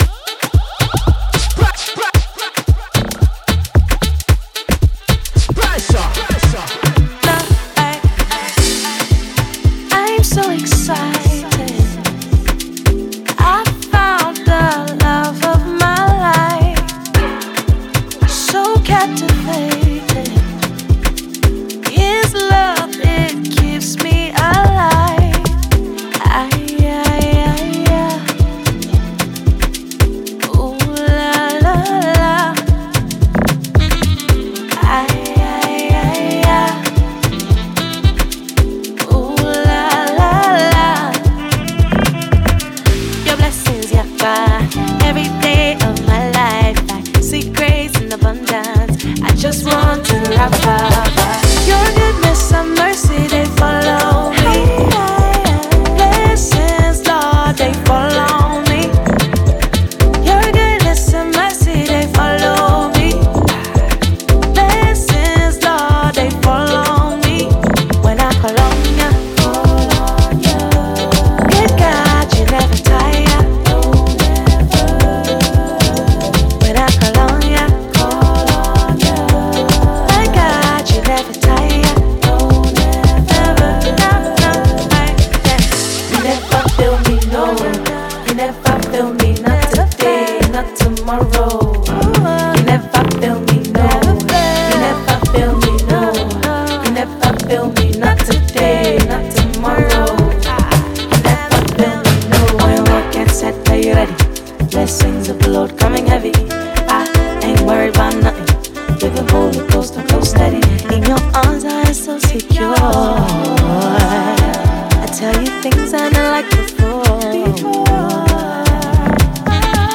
urban gospel